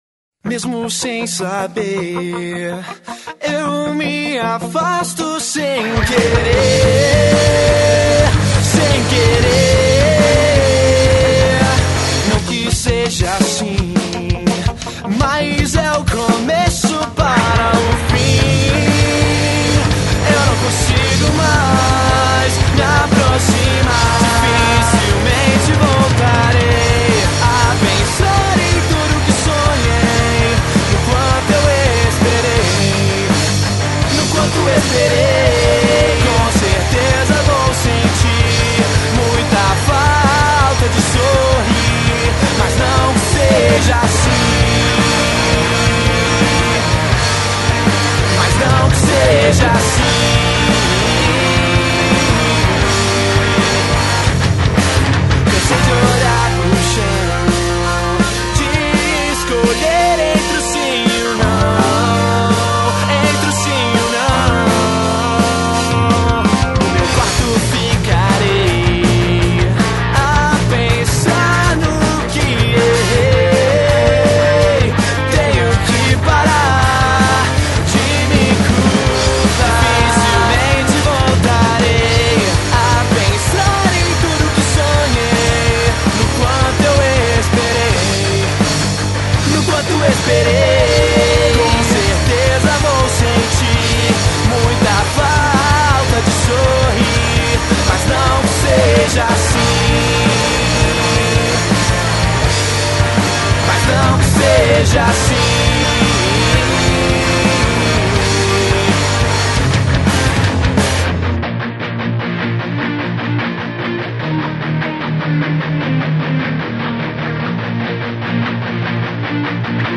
EstiloEmocore